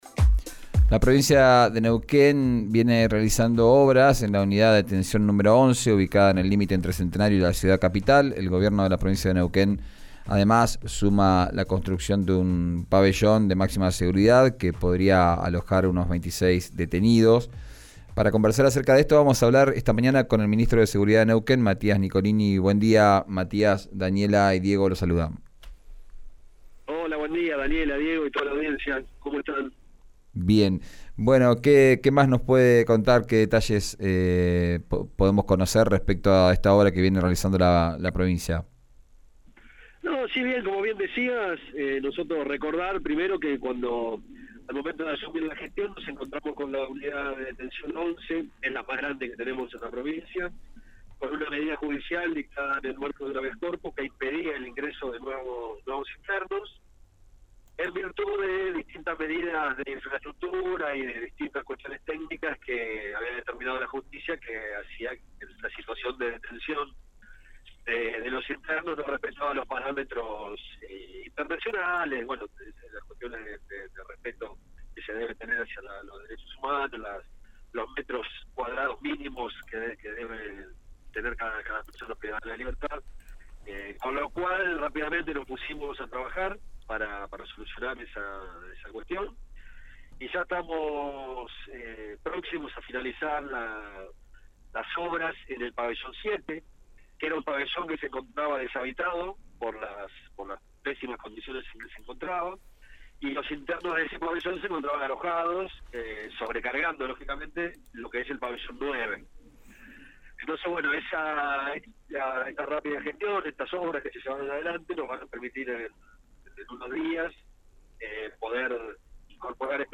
Escuchá a Matías Nicolini, ministro de Seguridad de Neuquén, en RÍO NEGRO RADIO:
El ministro de Seguridad de Neuquén, Matías Nicolini, habló sobre el caso del policía que mató con su arma reglamentaria a un joven en el oeste la noche del martes. En dialogo con RÍO NEGRO RADIO aseguró que se reforzaron las medidas de seguridad con la compra de nuevo equipamiento para los efectivos policiales.